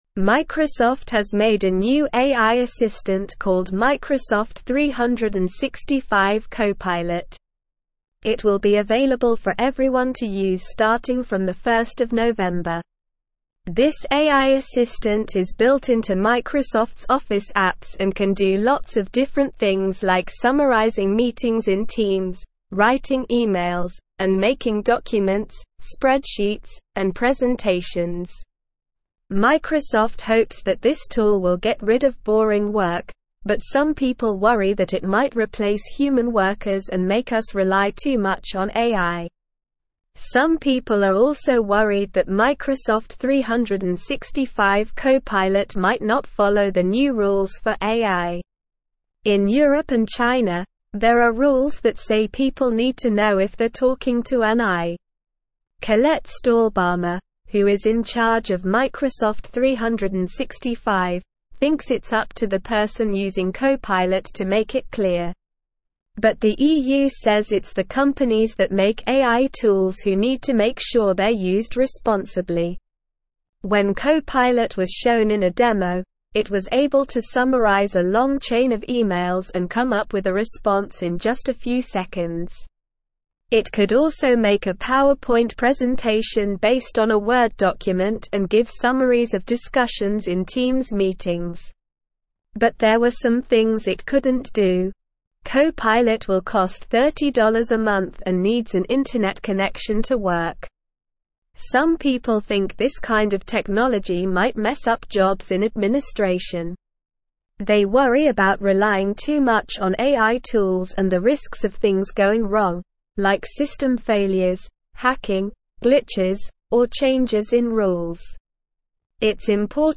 Slow
English-Newsroom-Upper-Intermediate-SLOW-Reading-Microsoft-365-Copilot-AI-Assistant-Raises-Job-Displacement-Concerns.mp3